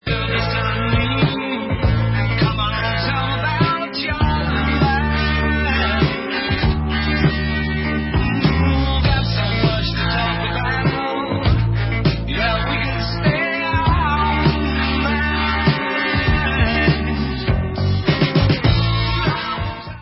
Rock/Progressive